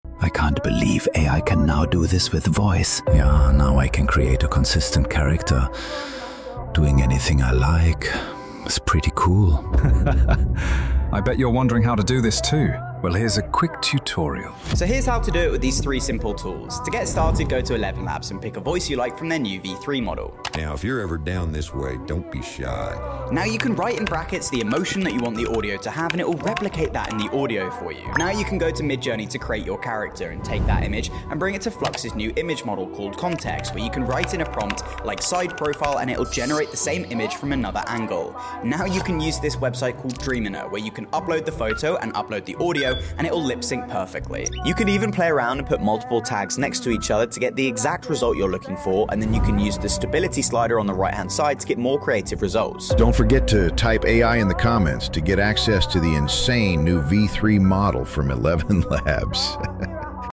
AI Voices just got a huge upgrade! 🔥 Tool: ElevenLabs